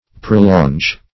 Prolonge \Pro*longe"\, n. [F. See Prolong.] (Field Artillery)